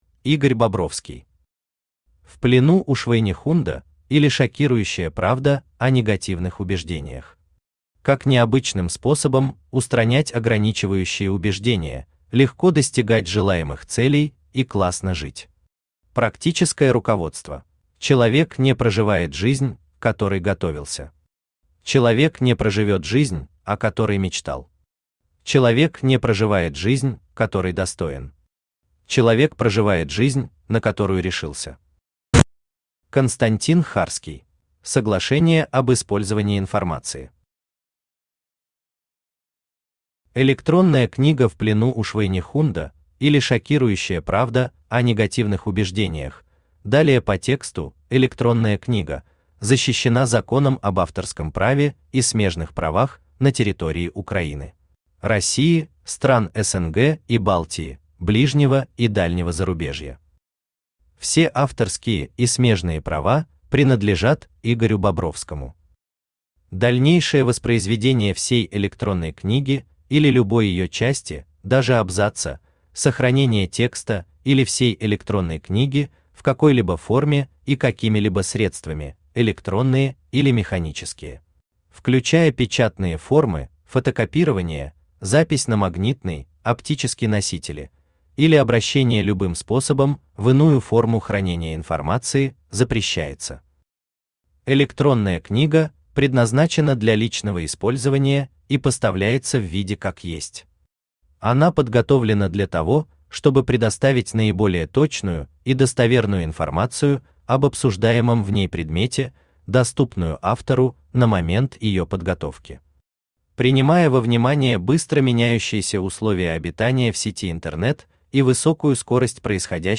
Аудиокнига В плену у Швайнехунда, или Шокирующая правда о негативных убеждениях.